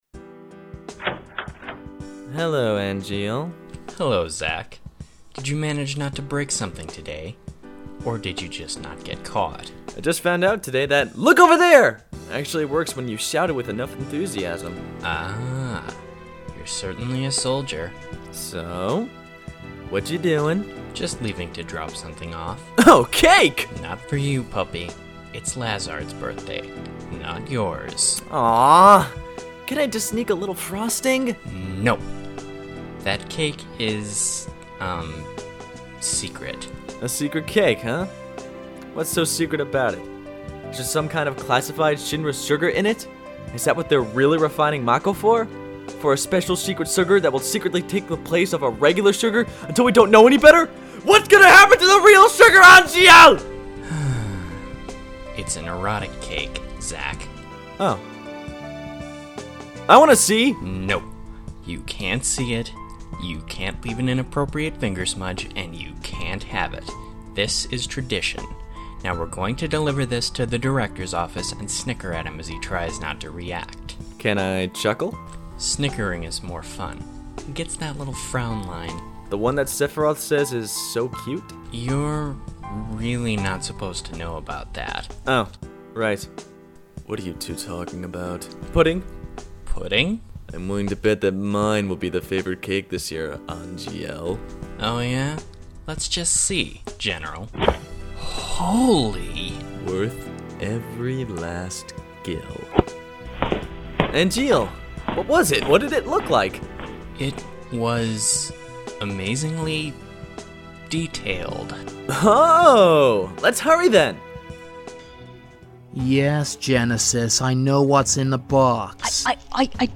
Audio Dramas